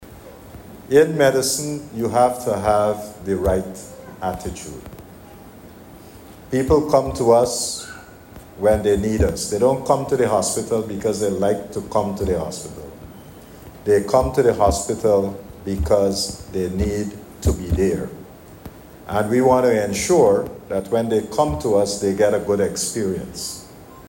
Minister of Health, Dr. Frank Anthony, while delivering the keynote address congratulated the graduates and further highlighted the Government’s commitment to expanding infrastructure in the health sector, and also improving service delivery by building a more trained workforce.